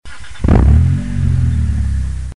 Рев мотора спортивного автомобиля при заводе